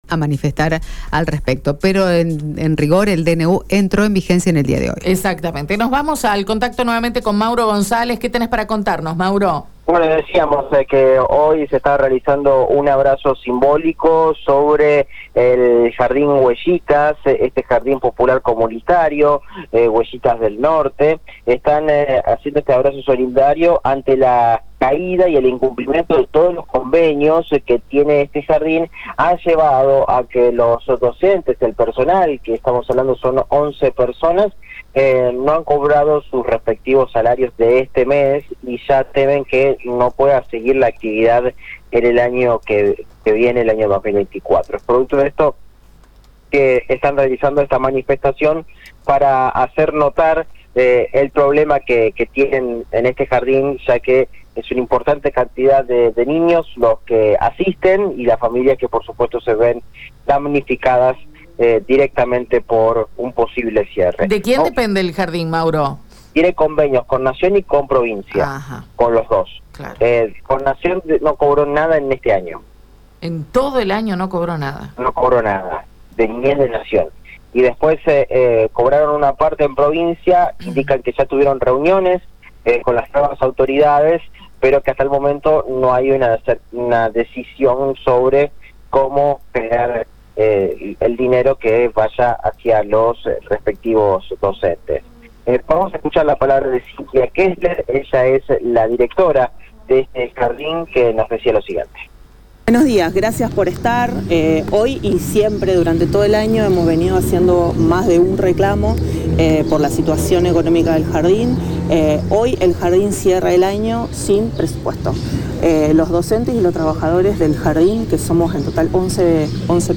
En Gorostiaga al 4400, frente al Hipódromo, se encuentra el Jardín popular comunitario "Huellitas" donde padres y docentes realizaron hoy un abrazo simbólico pidiendo a provincia se generen los recursos para que la institución no cierre.